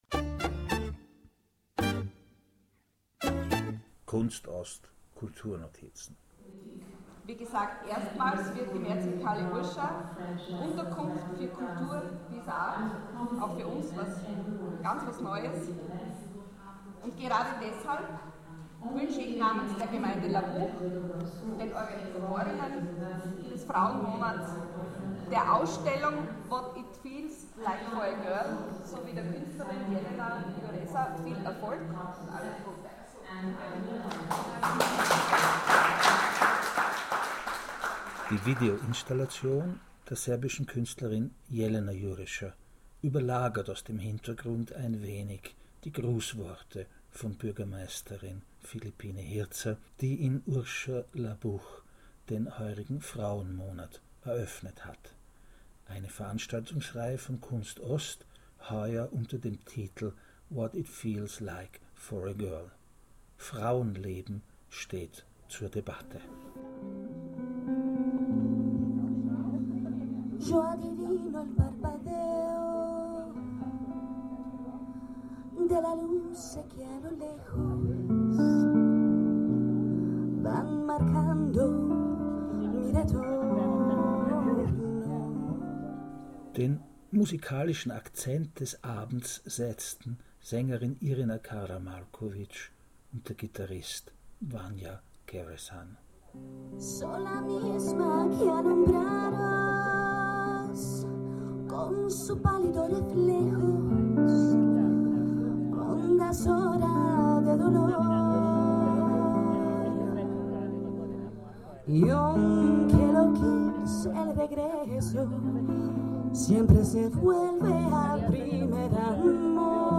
urscha/labuch, bürgermeisterin phillipine hierzer eröffnet den "frauenmonat" von "kunst ost"